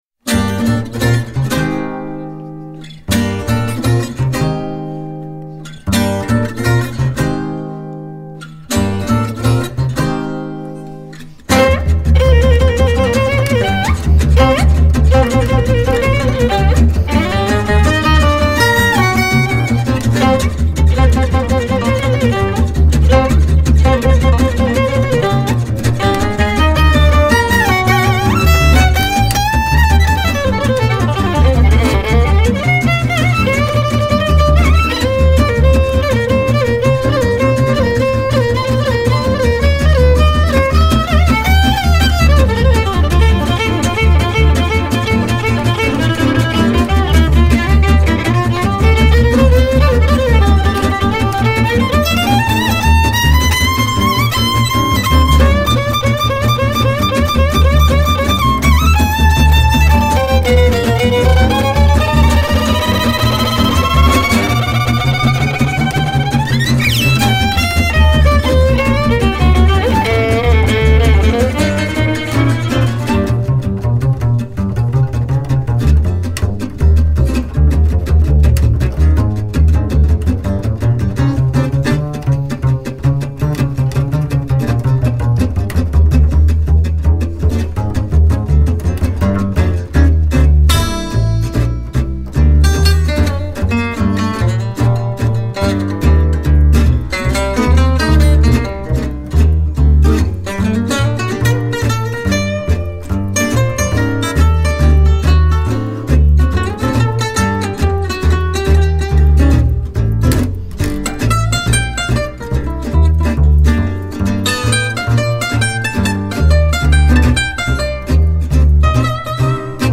trombone
guitare
contrebasse
batterie